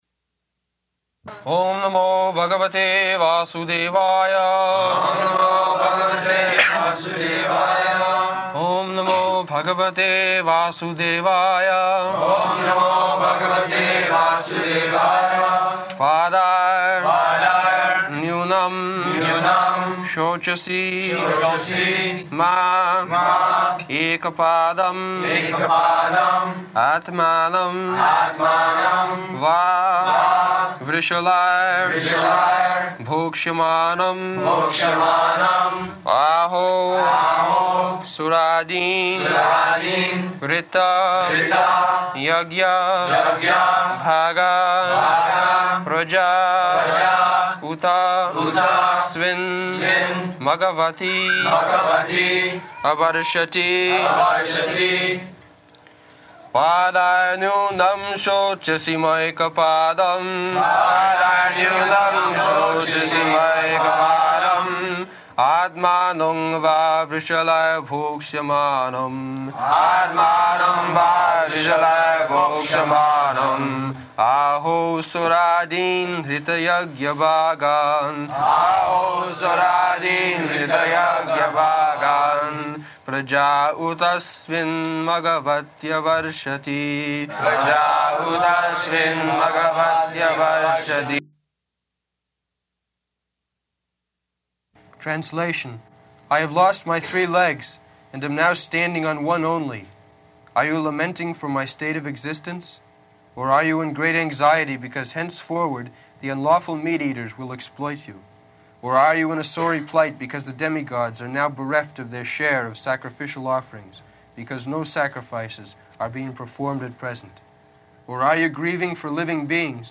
January 16th 1974 Location: Honolulu Audio file
[devotees repeat] [leads chanting of verse, etc.]